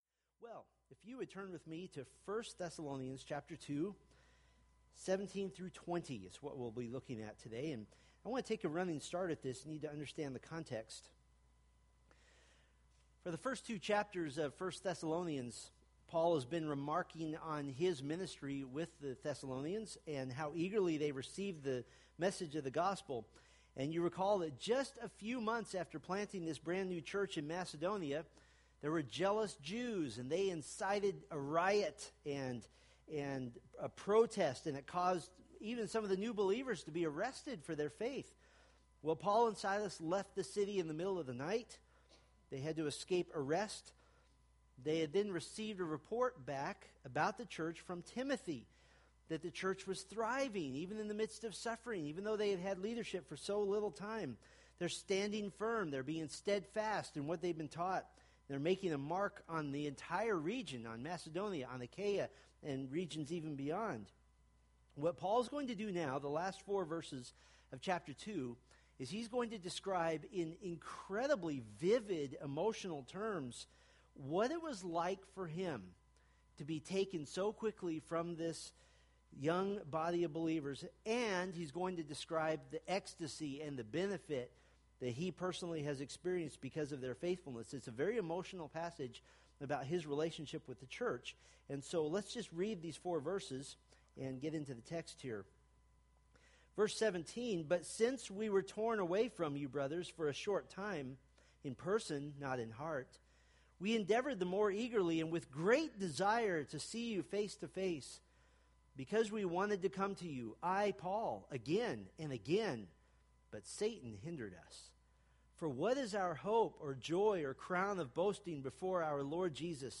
Homepage of Steadfast in the Faith, anchoring the soul in the Word of God by providing verse-by-verse exposition of the Bible for practical daily living.